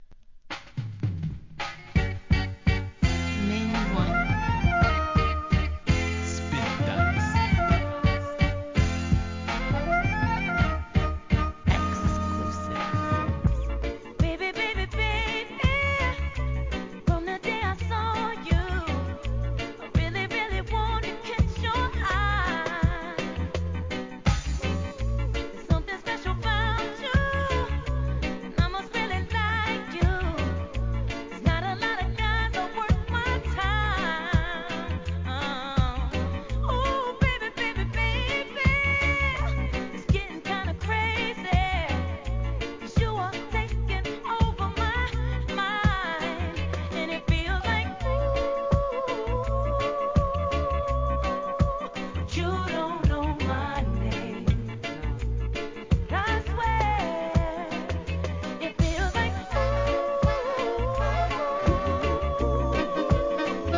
¥ 770 税込 関連カテゴリ REGGAE 店舗 ただいま品切れ中です お気に入りに追加